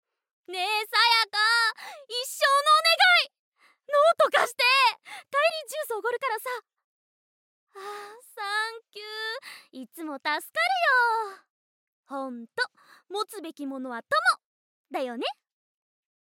ボイスサンプル
女子高校生